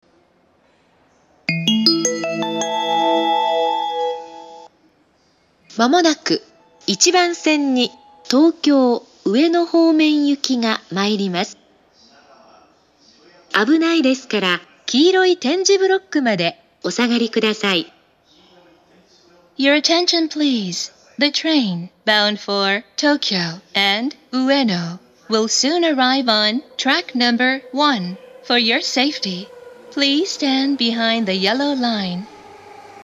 １番線接近放送